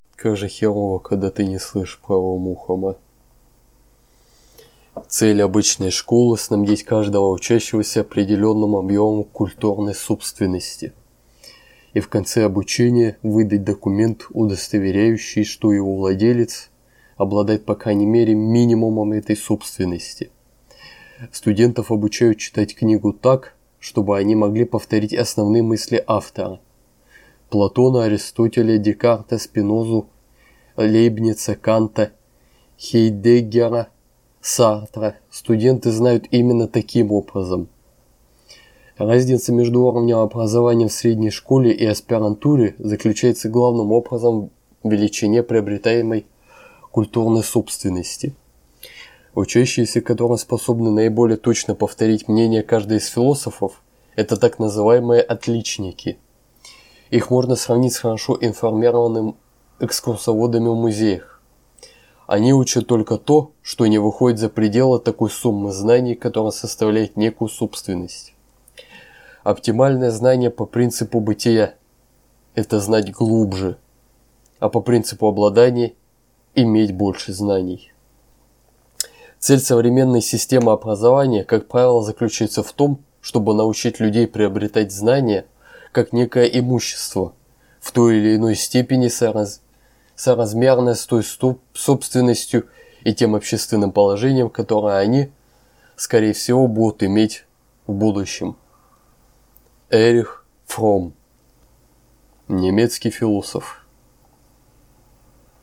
Улучшить качество записи петлички-микрофона